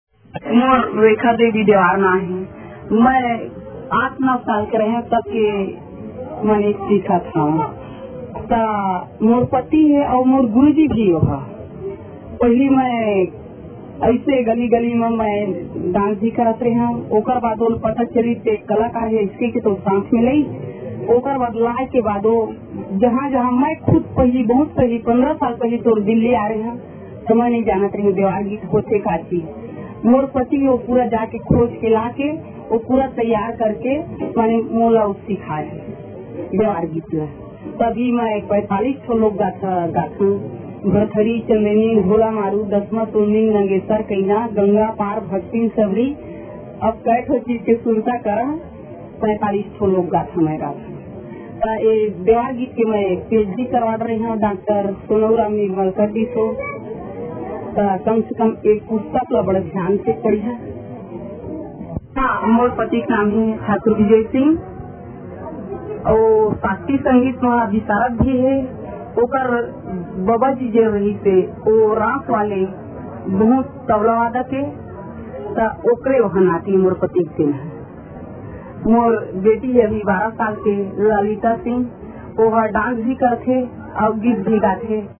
एक साक्षातकार